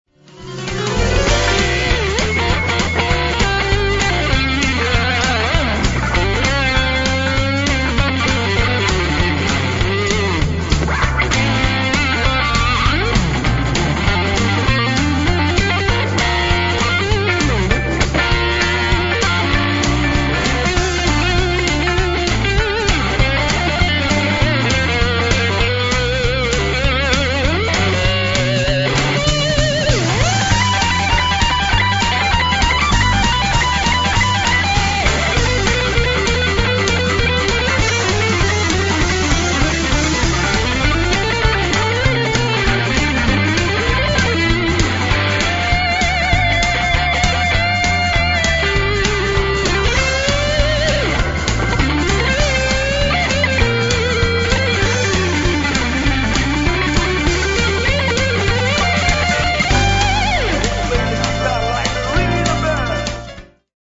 brzi blues-country